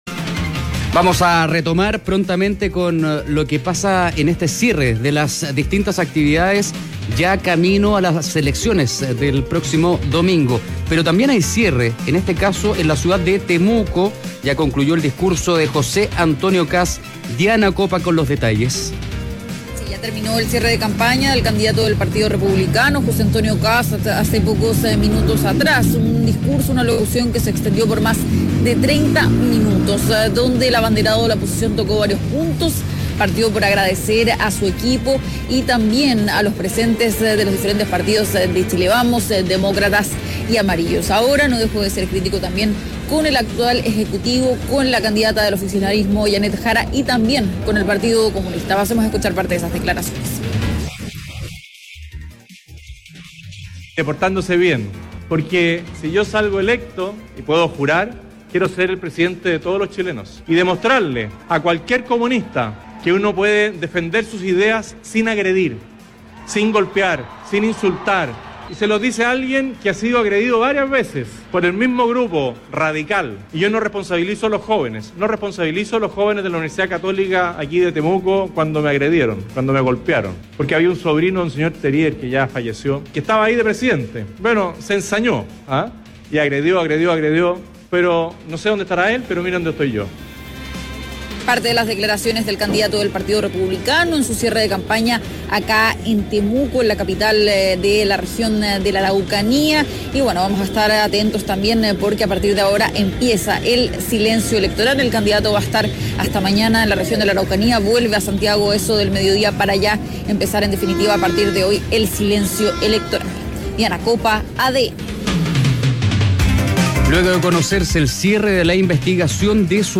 Cierre de camapaña en Temuco de José Antonio Kast